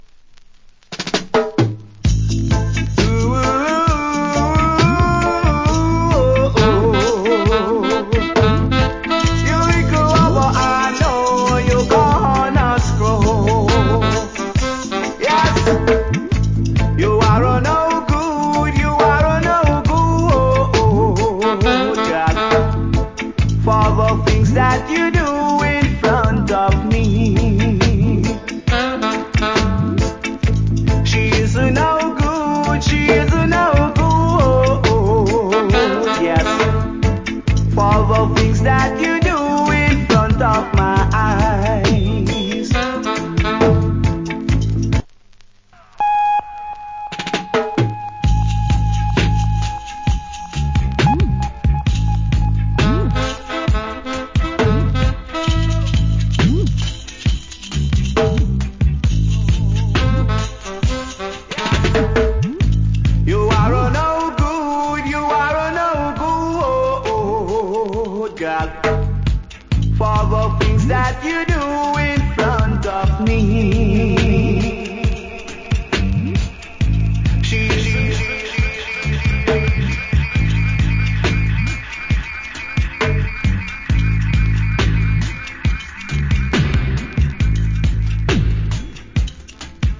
Early 80's. Cool Roots Rock Vocal.